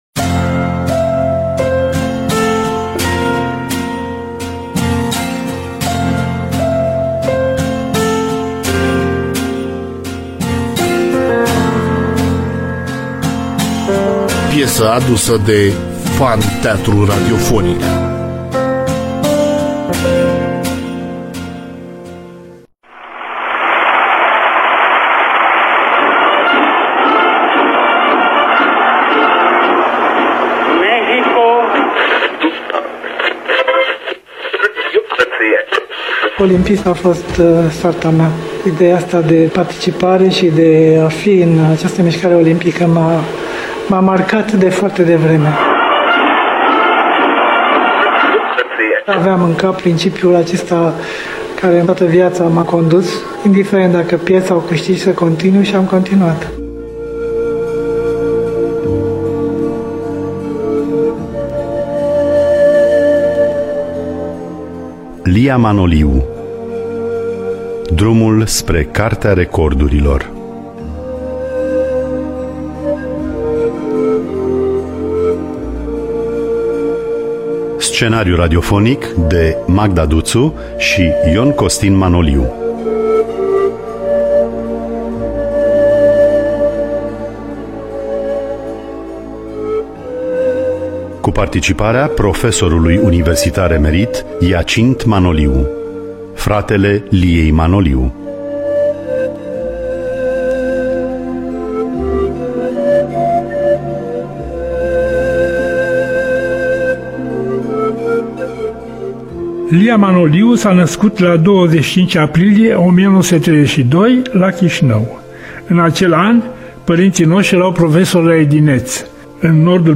În rolul titular – Maria Ploae.